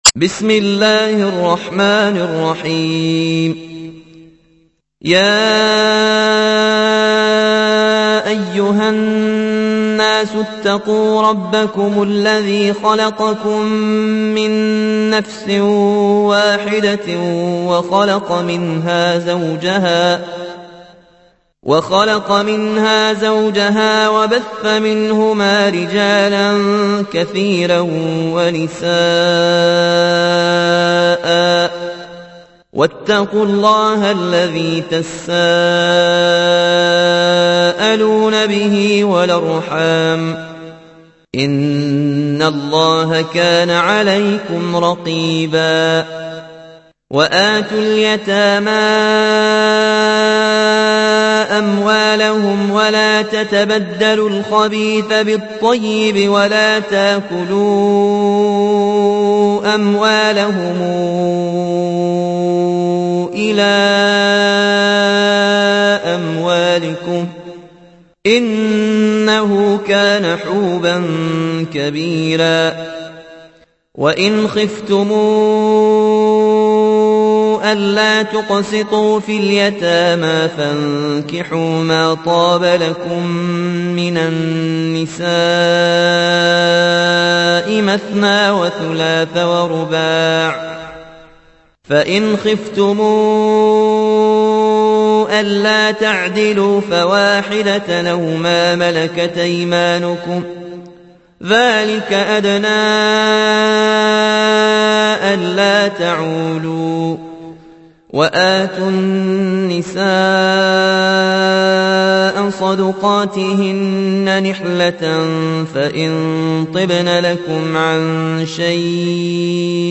سورة النساء / القارئ